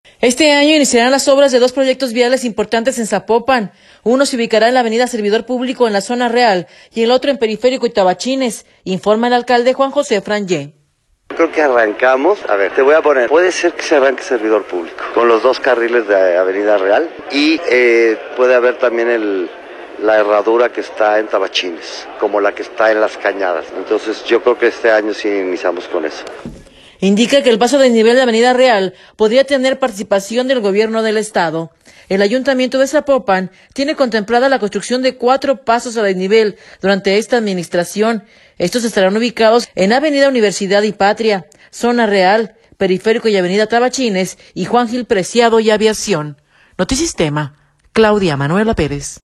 Este año iniciarán las obras de dos proyectos viales importantes en Zapopan. Uno se ubicará en la avenida Servidor Público, en la Zona Real, y el otro en Periférico y Tabachines, informa el alcalde Juan José Frangie.